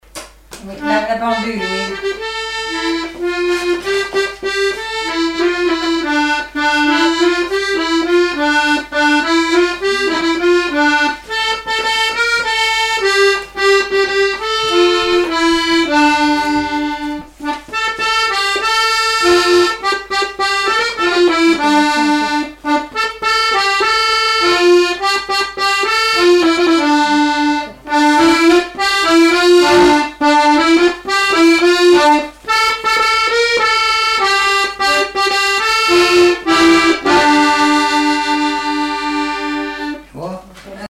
Résumé instrumental
Répertoire du musicien sur accordéon chromatique
Pièce musicale inédite